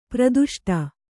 ♪ praduṣṭa